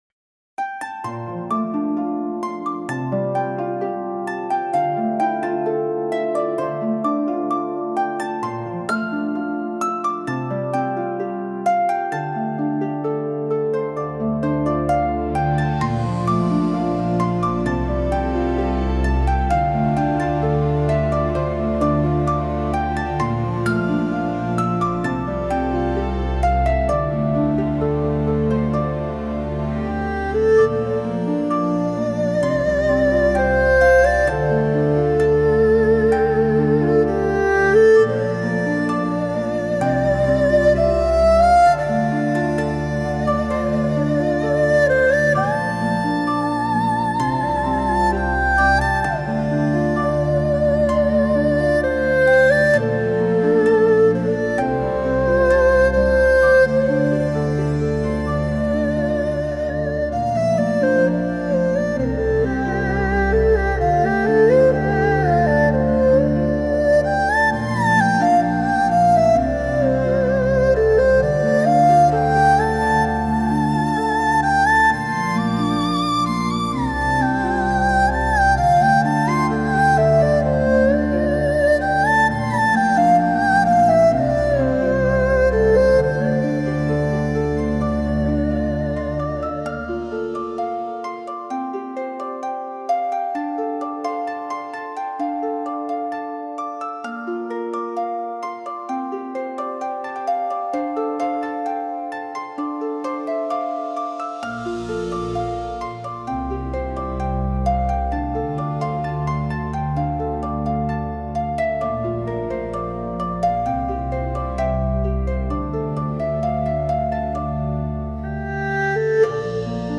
和風バラード・インストゥルメンタル・ボーカル無し